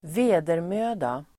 Uttal: [²v'e:dermö:da]